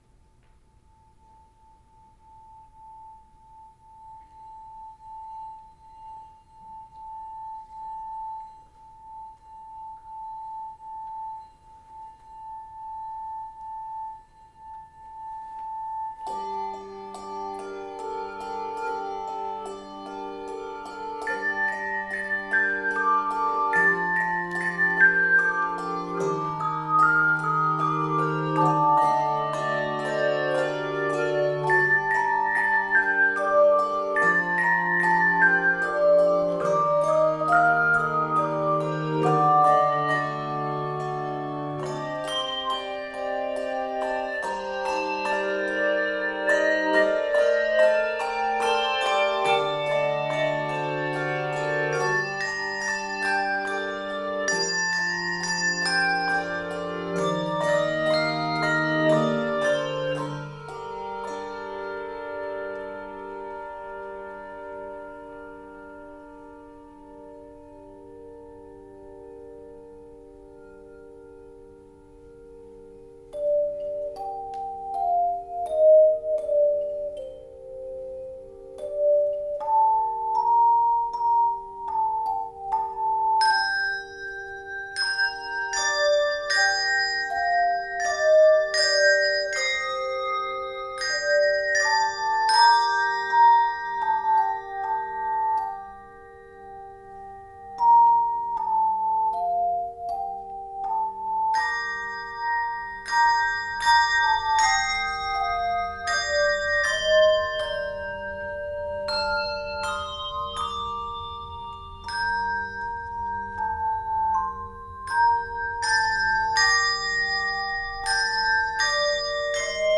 Key of g minor.